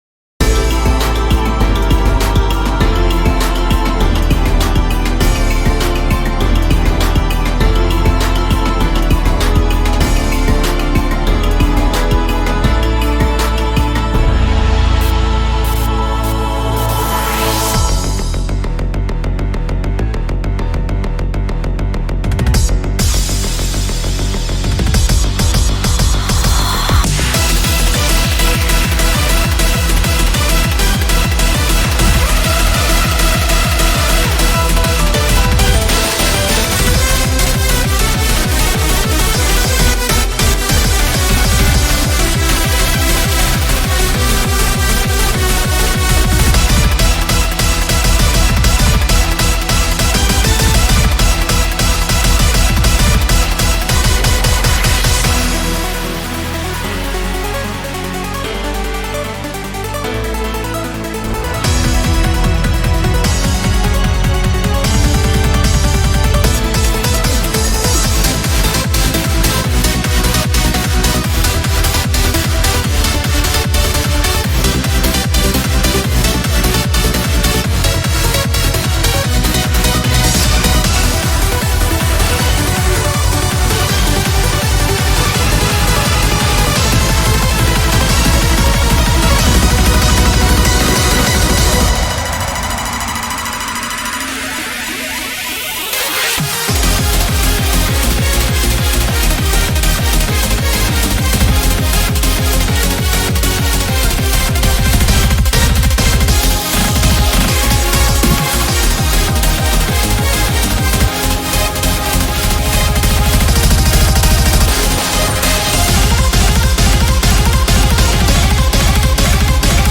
BPM20-800
Audio QualityCut From Video